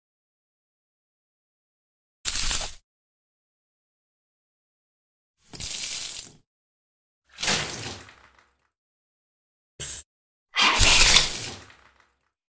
Screech Sound Effect Is Scary!
Screech sound effect is scary! sound effects free download